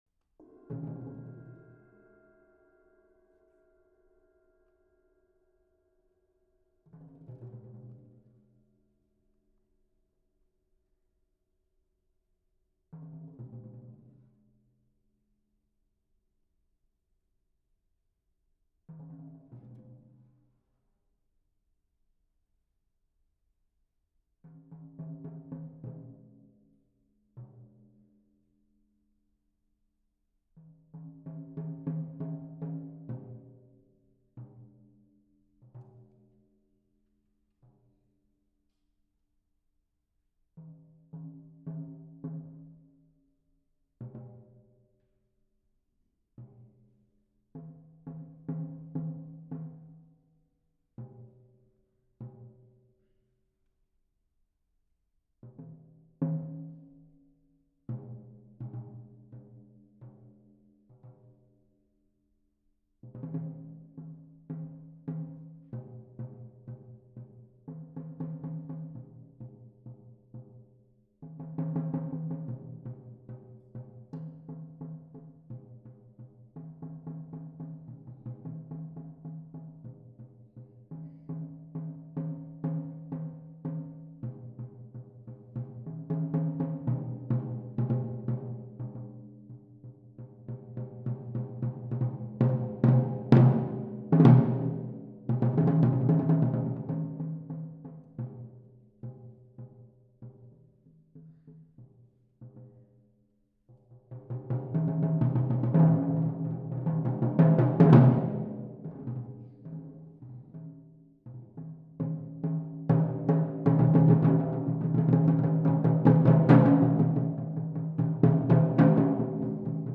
for solo percussionist
two double-headed tom-toms and a large, dark, heavy cymbal
(The piece begins very softly.)